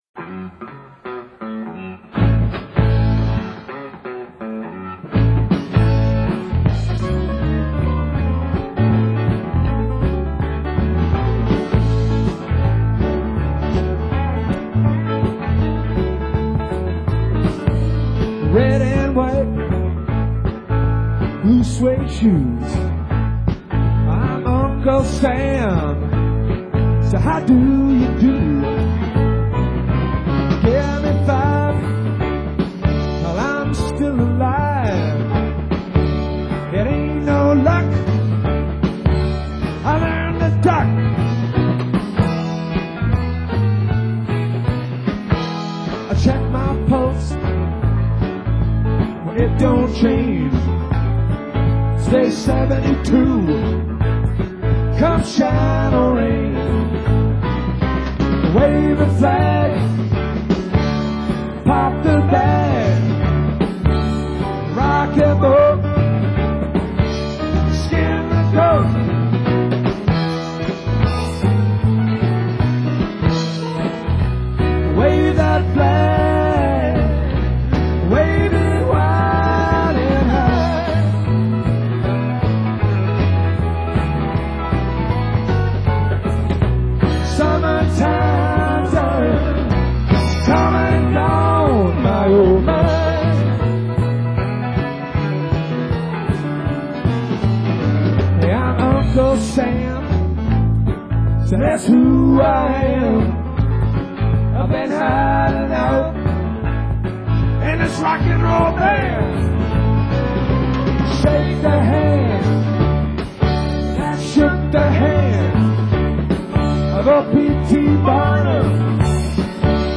using a line level direct from the mixing consule.
keyboards & vocals
drums
bass & vocals
guitar & vocals